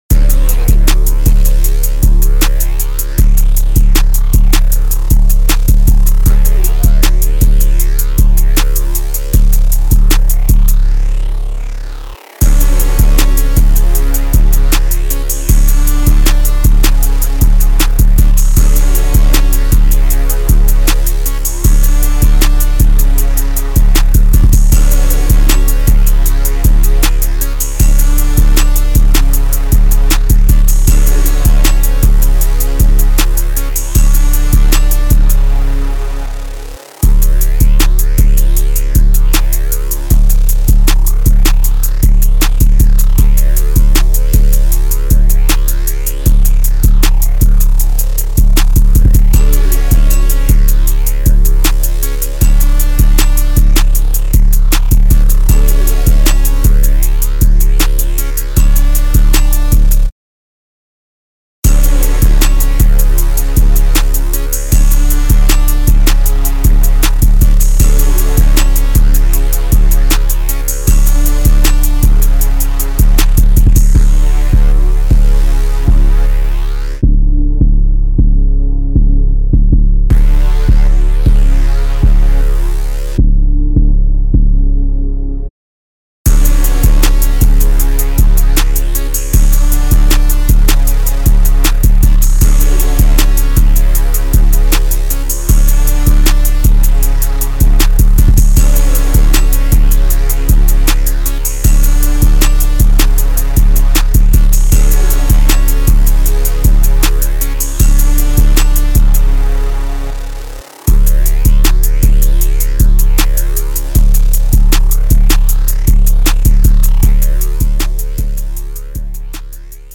Here's the official instrumental